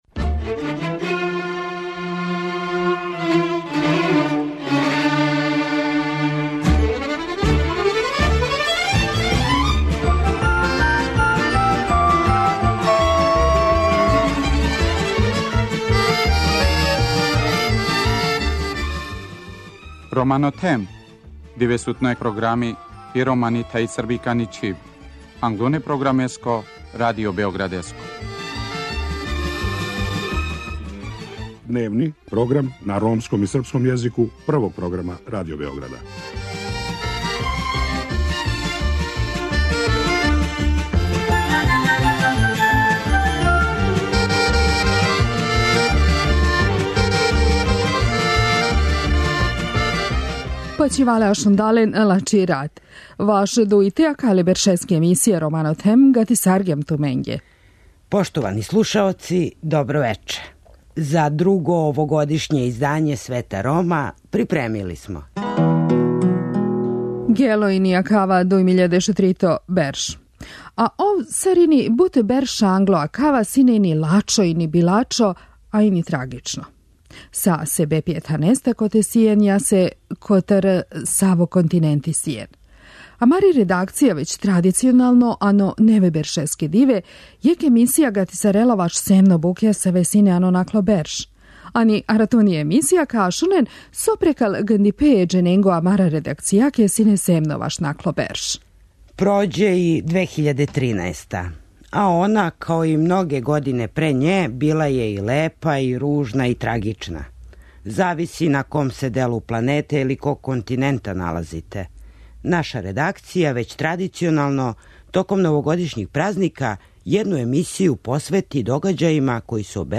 Они ће бирати музичке нумере које обележавају њихово музичко стваралаштво.